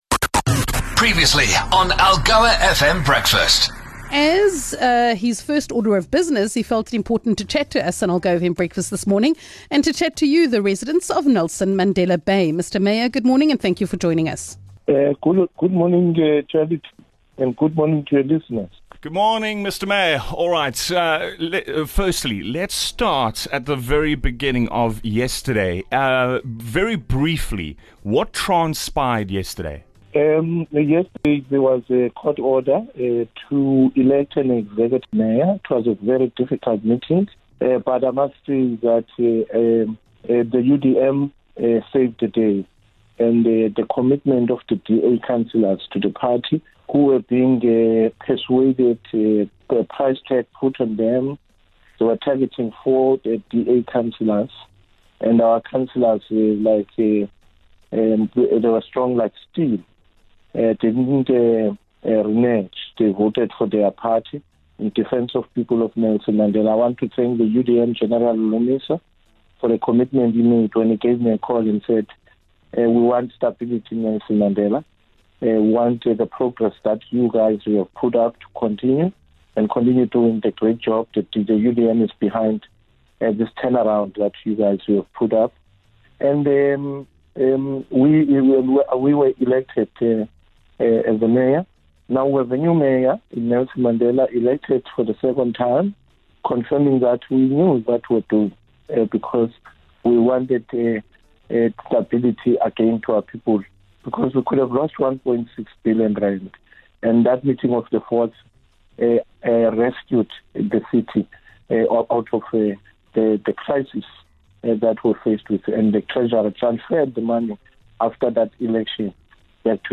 After being officially elected as the mayor of Nelson Mandela Bay following a court-ordered process, Bhanga made sure one of his first port of calls was Algoa FM Breakfast.